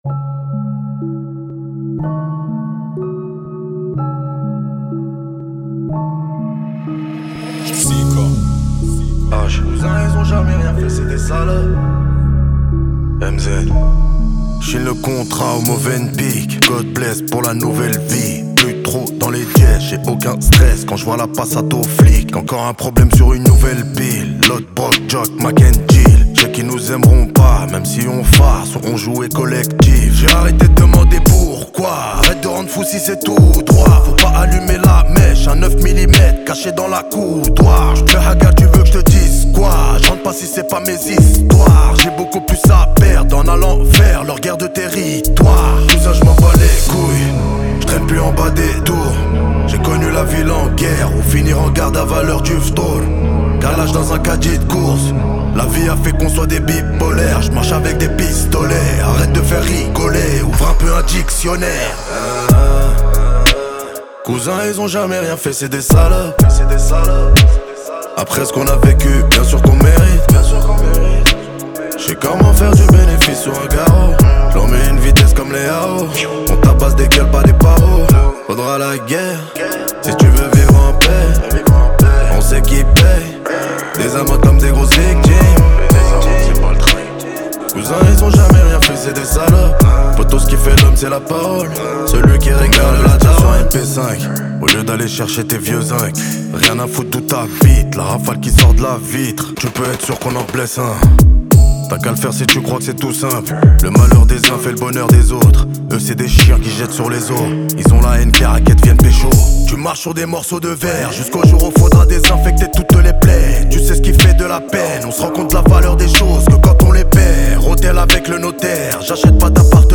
43/100 Genres : french rap Télécharger